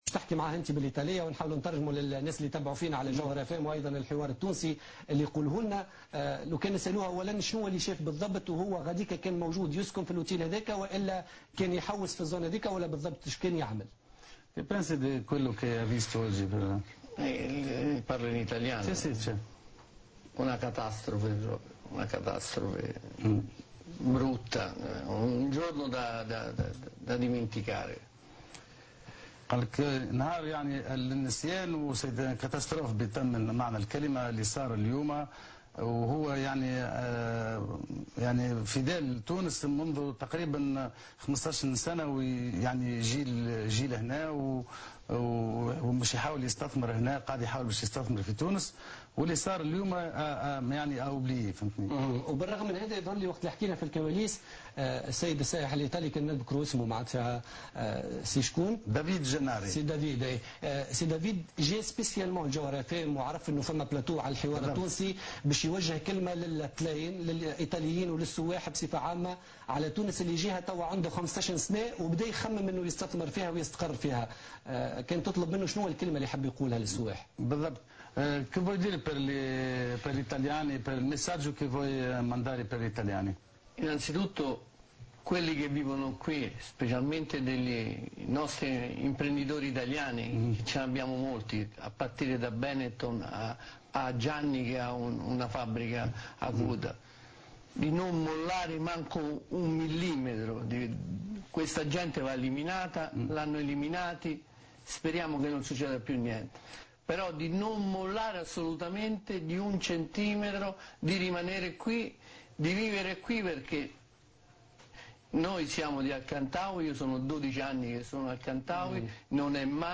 Un italien adresse un message aux touristes en Tunisie